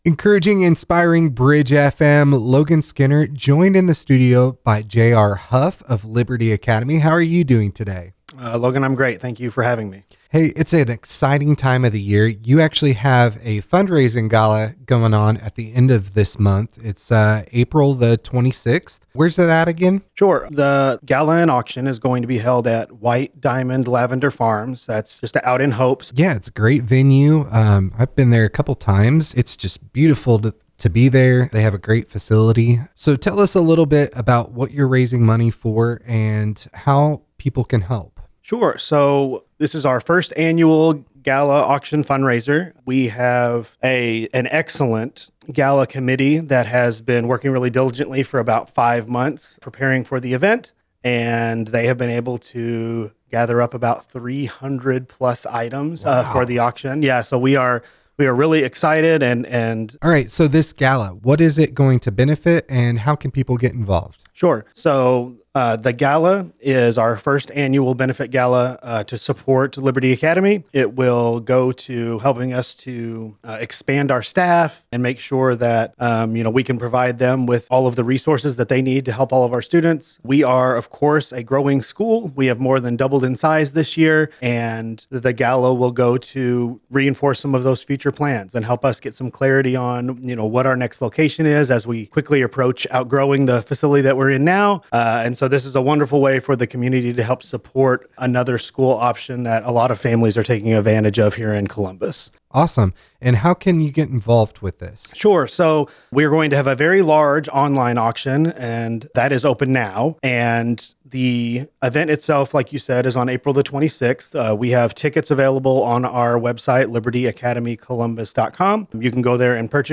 Liberty Academy Gala 2025 Interview